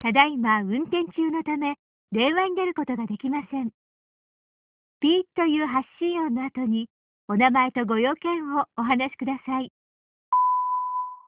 JapaneseAnswer2.amr